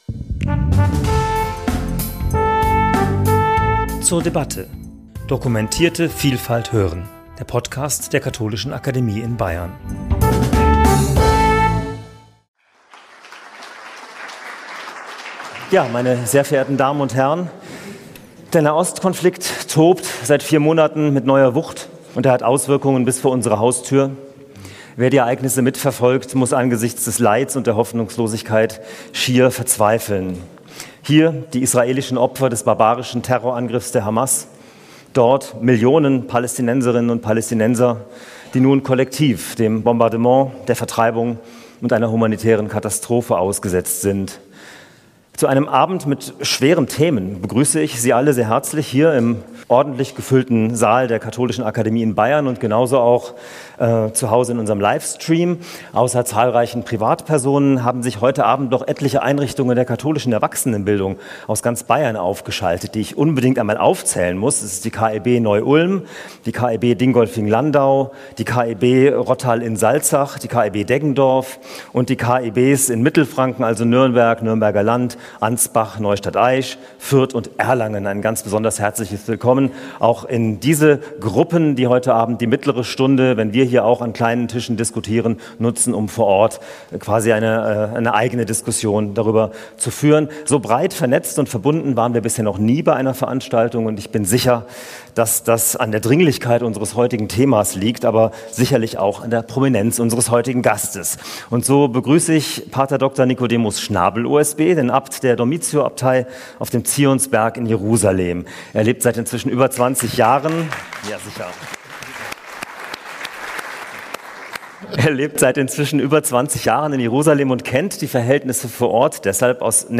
Kann man noch auf Frieden hoffen? Unser Gast ist P. Dr. Nikodemus Schnabel OSB, Abt der Dormitio-Abtei in Jerusalem, der die Verhältnisse vor Ort aus besonderer Perspektive kennt. Wir sprechen mit ihm über die aktuelle Situation im Nahen Osten, das Zusammenleben verschiedener Religionen und Kulturen sowie die Lage der christlichen Bevölkerung.